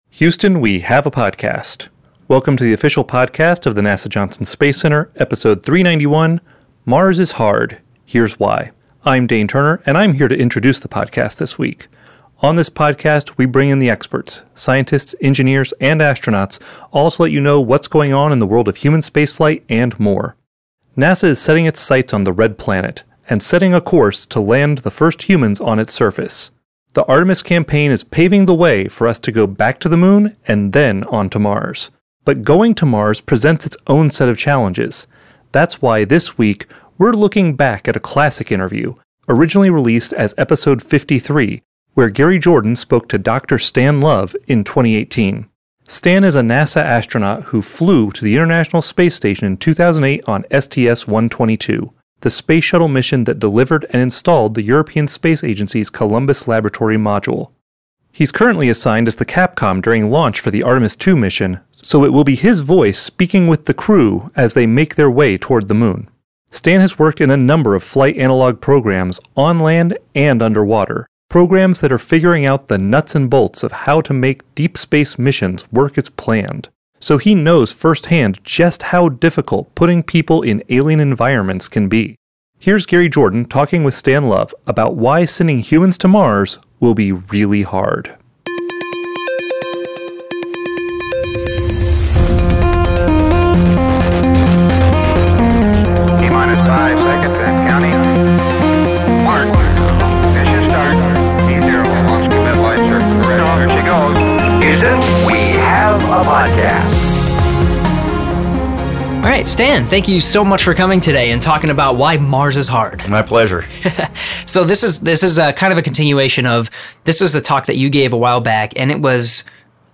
Listen to in-depth conversations with the astronauts, scientists and engineers who make it possible.
On episode 391, NASA astronaut Stan Love discusses the challenges of sending humans to Mars and what it will take to get us to the Red Planet.